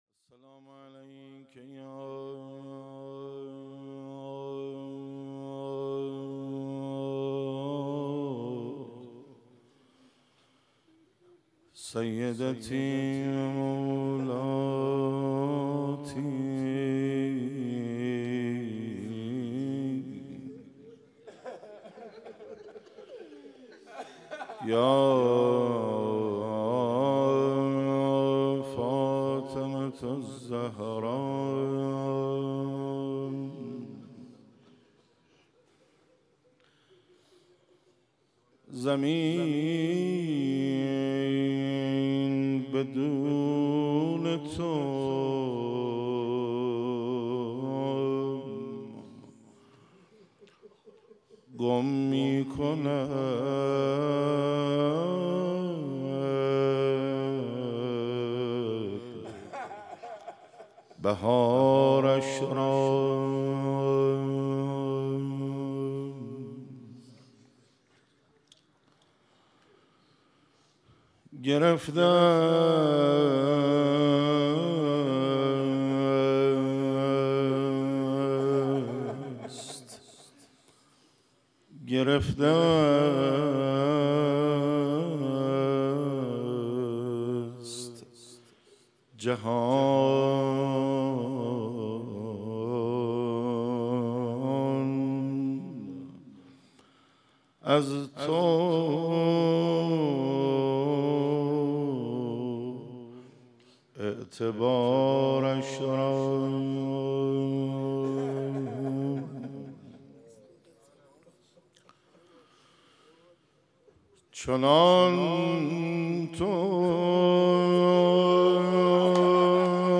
حسینیه انصارالحسین علیه السلام
روضه - زمین بدون تو گم میکند بهارش را